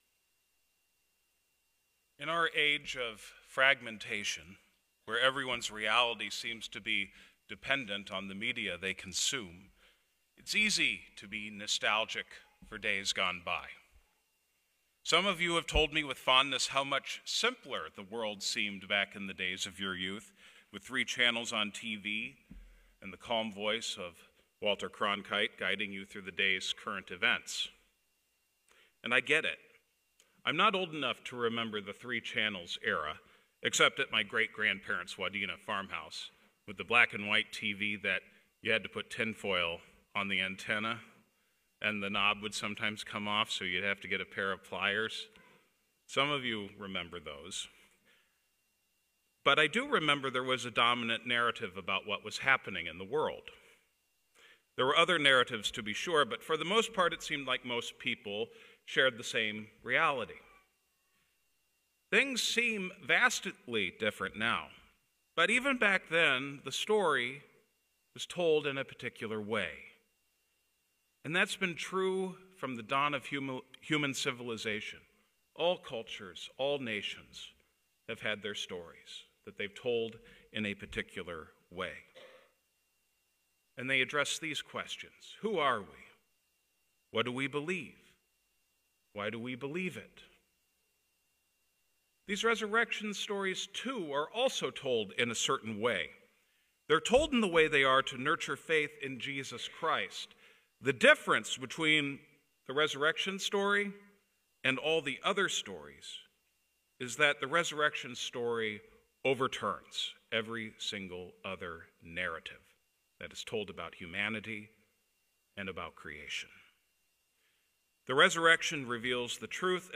Sermons | Shalom Lutheran Church